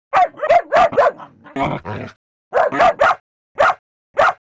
dog.wav